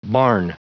Prononciation du mot barn en anglais (fichier audio)
Prononciation du mot : barn